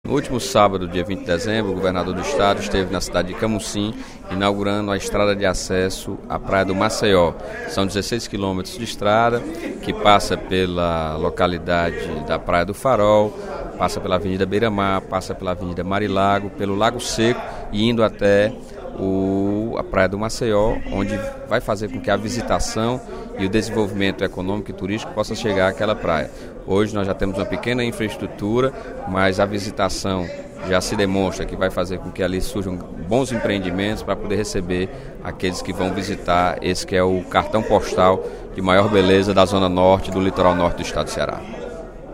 Em pronunciamento no primeiro expediente da sessão plenária desta terça-feira (23/12), o deputado Sérgio Aguiar (Pros) destacou a inauguração da estrada de 16km, que liga Camocim à praia do Maceió.